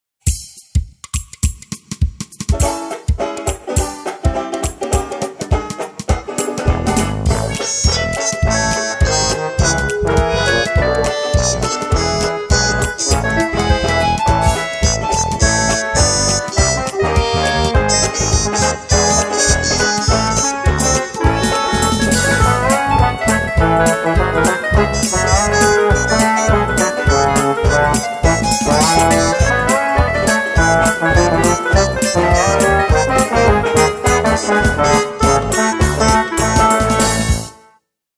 из детского инструментального альбома